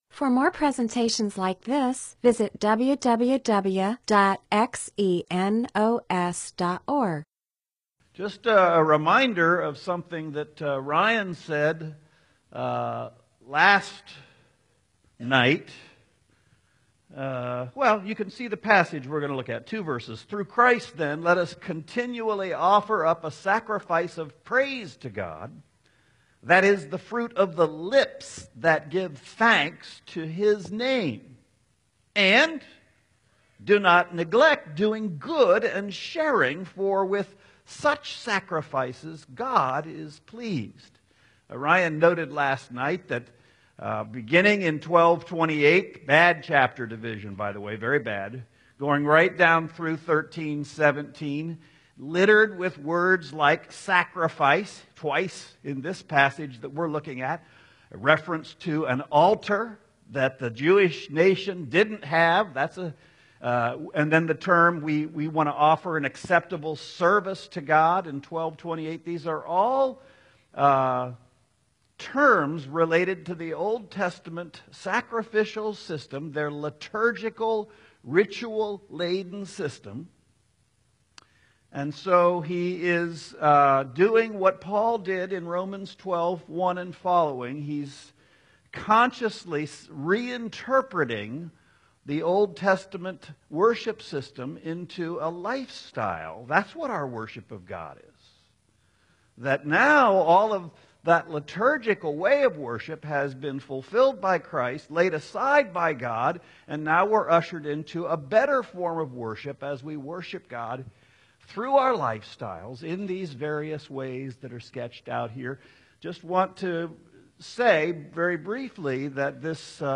MP4/M4A audio recording of a Bible teaching/sermon/presentation about Hebrews 13:15-16; Ephesians 3:3-4; James 1:17.